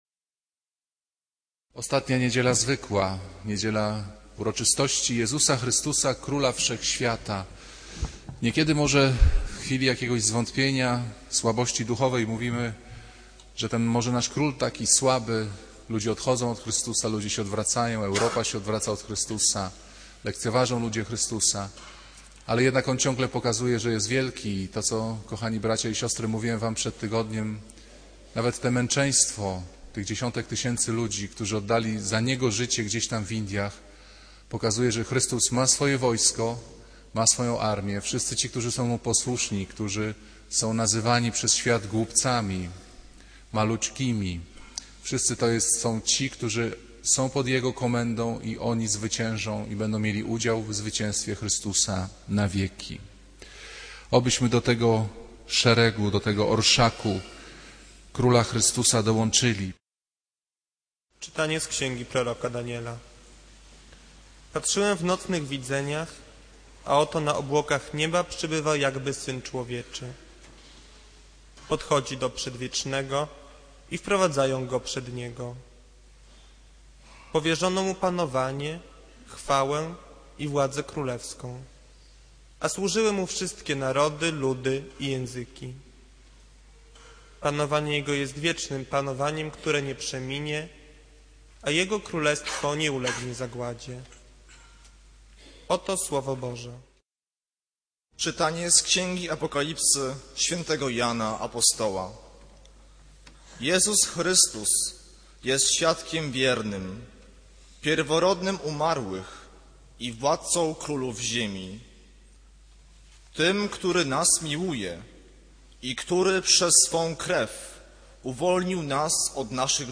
Kazanie z 24 maja 2009r.